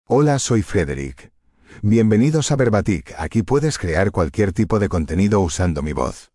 Frederick — Male Spanish (Spain) AI Voice | TTS, Voice Cloning & Video | Verbatik AI
FrederickMale Spanish AI voice
Frederick is a male AI voice for Spanish (Spain).
Voice sample
Listen to Frederick's male Spanish voice.
Frederick delivers clear pronunciation with authentic Spain Spanish intonation, making your content sound professionally produced.